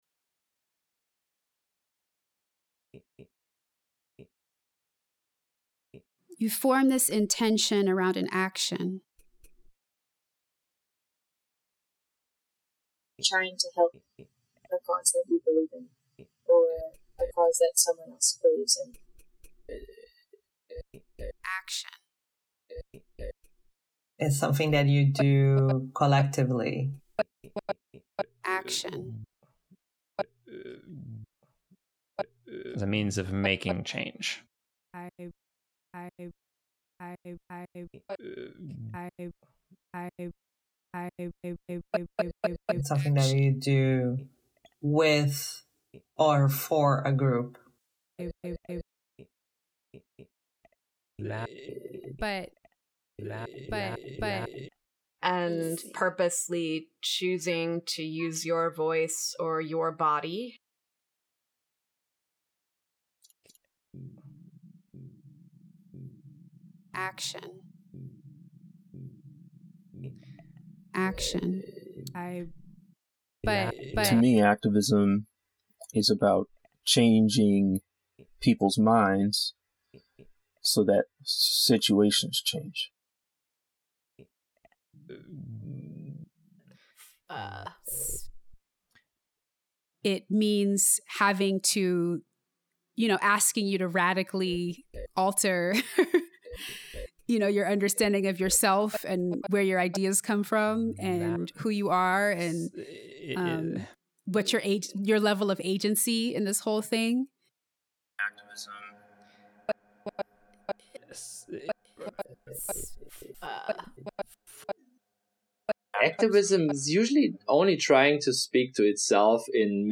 format: mp3 stereo